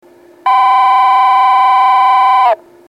１番線発車ベル
（軽井沢より）   軽井沢より（最後部付近）の電子電鈴での収録です。